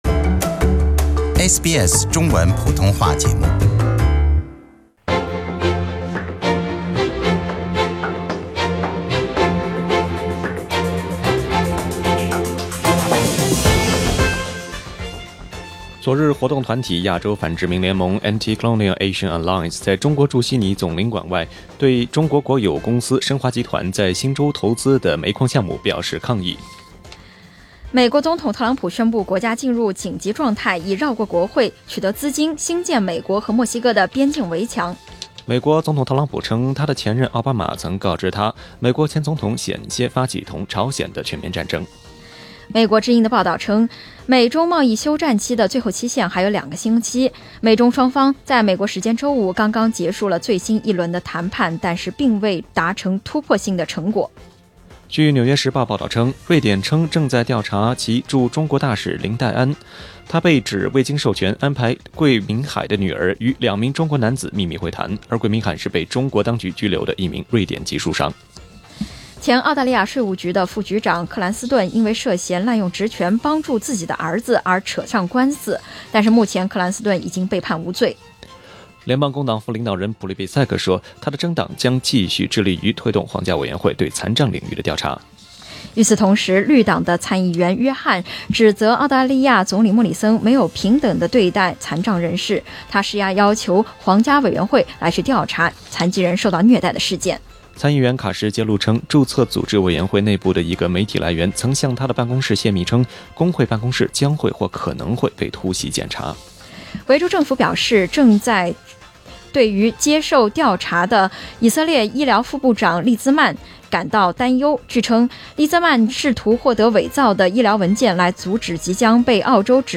SBS早新聞（2月16日）